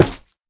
Minecraft Version Minecraft Version snapshot Latest Release | Latest Snapshot snapshot / assets / minecraft / sounds / block / froglight / break3.ogg Compare With Compare With Latest Release | Latest Snapshot
break3.ogg